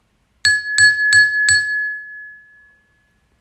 Plato y Cuchara
Bell Ding Ting sound effect free sound royalty free Sound Effects